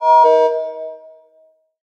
paEndReverb.ogg